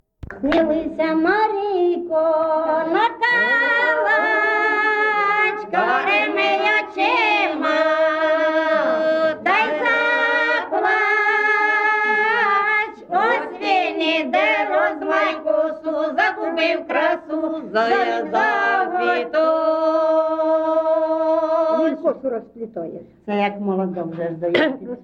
ЖанрВесільні
Місце записус. Писарівка, Золочівський район, Харківська обл., Україна, Слобожанщина